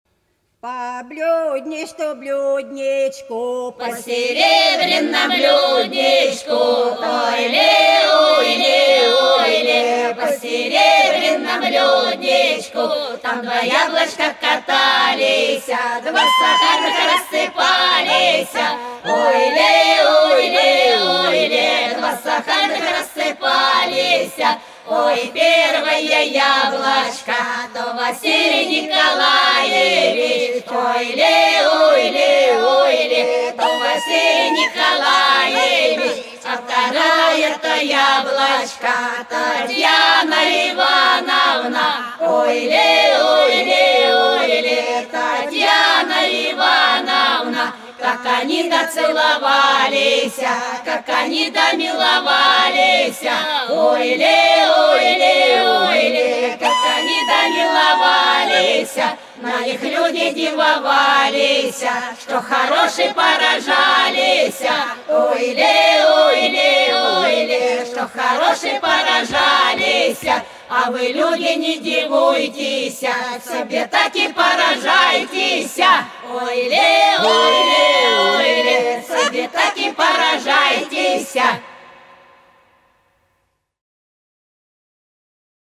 По-над садом, садом дорожка лежала По серебрянному блюдечку - свадебная (с. Фощеватово, Белгородская область)
22_По_серебрянному_блюдечку_(свадебная).mp3